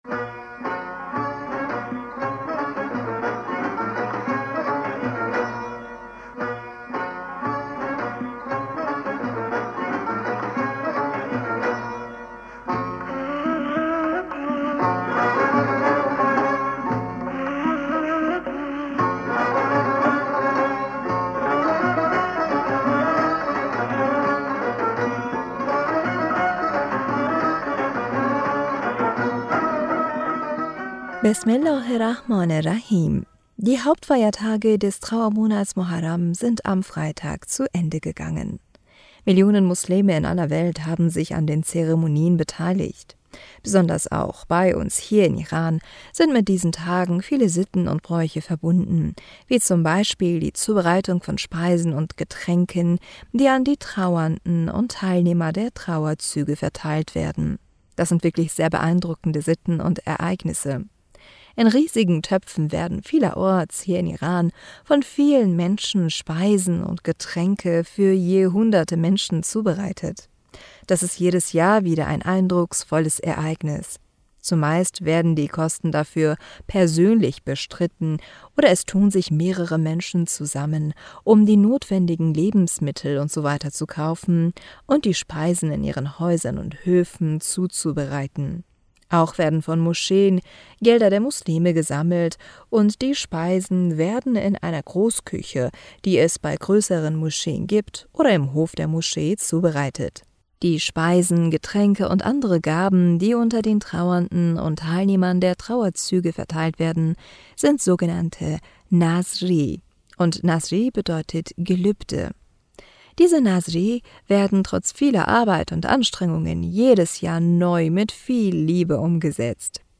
Hörerpostsendung am 30.Juli 2023.